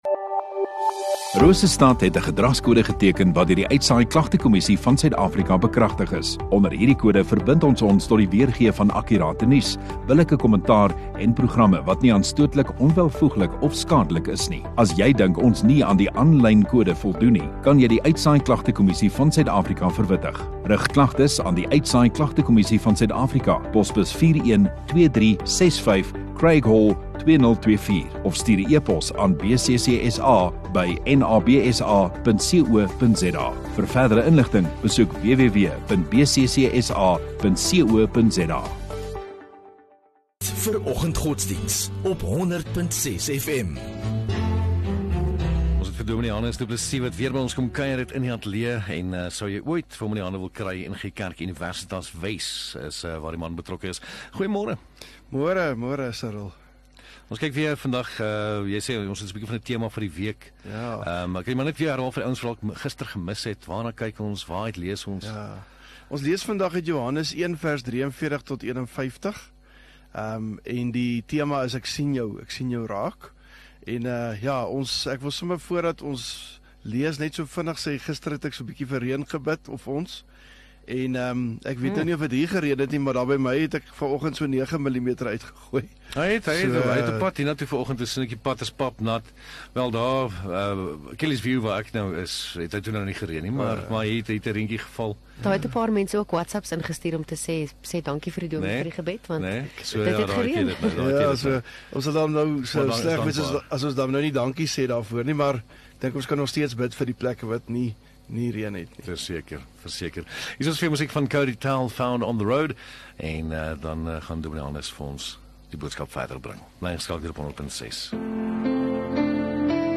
13 Mar Woensdag Oggenddiens